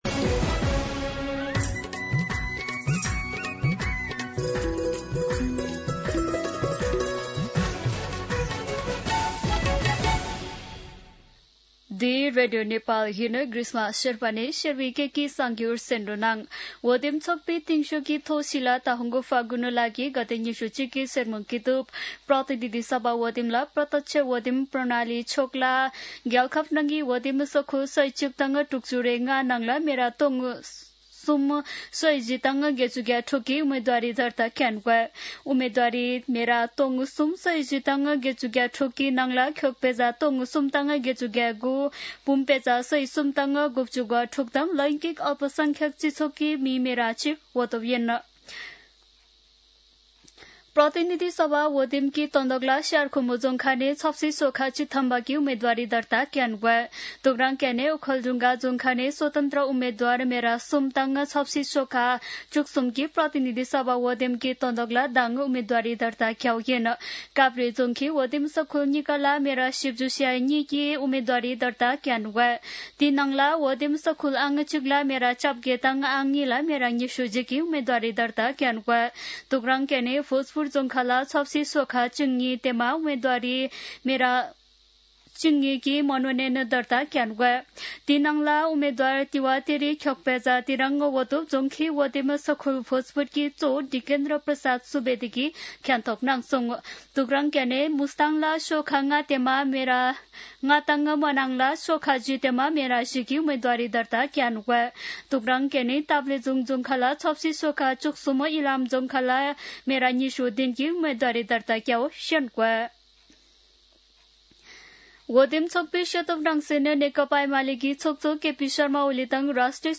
शेर्पा भाषाको समाचार : ७ माघ , २०८२
Sherpa-News-10-7.mp3